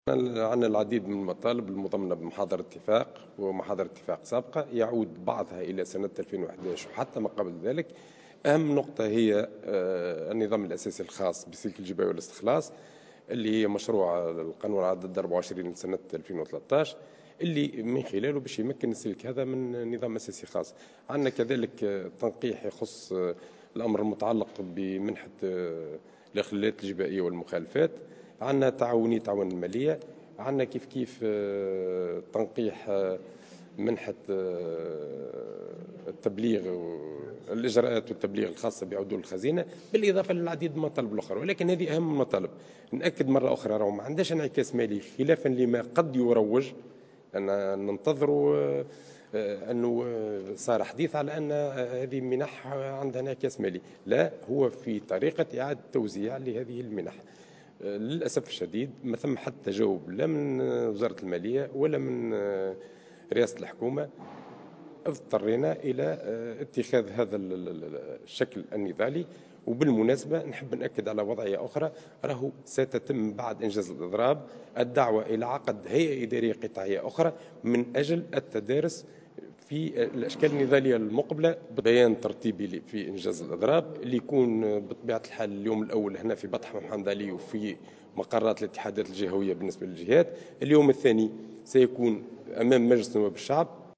في تصريح لمراسل "الجوهرة أف أم"